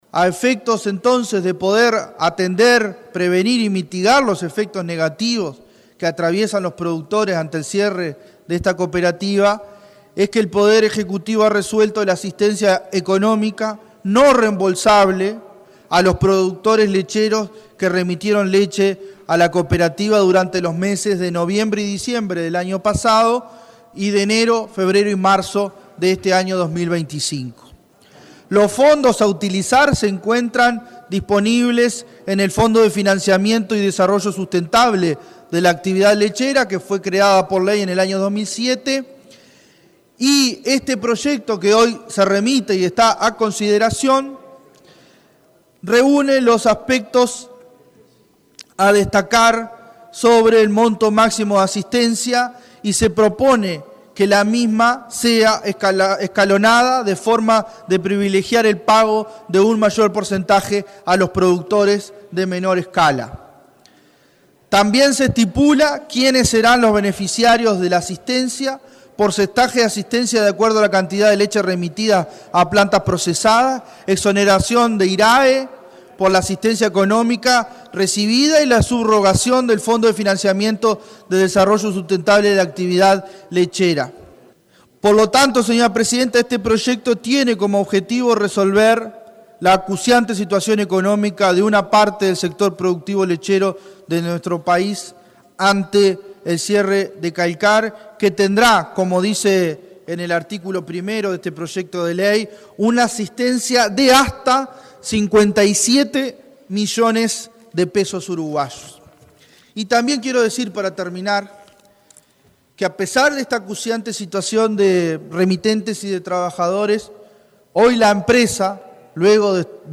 El senador Nicolás Viera fue el miembro informante.